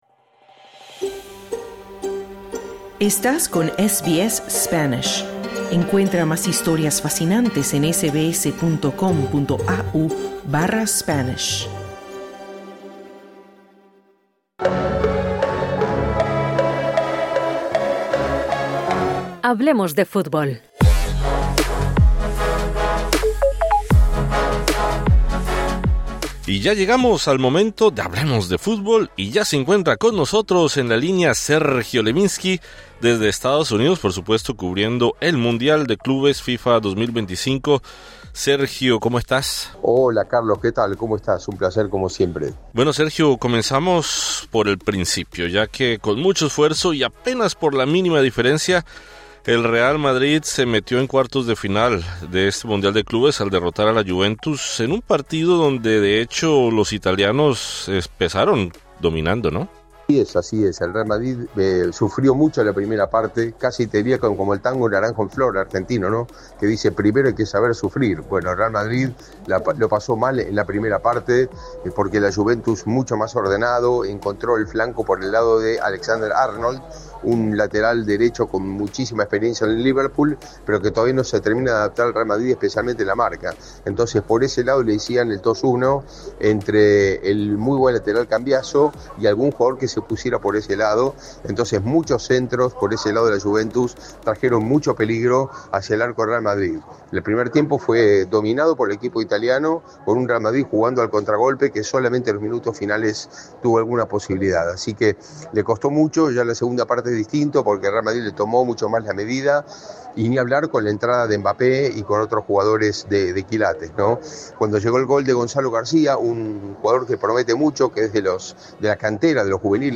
El equipo español afrontará el domingo de madrugada en Australia al Borussia Dortmund en Nueva Jersey. Escucha toda la información de este torneo con nuestro enviado especial a Estados Unidos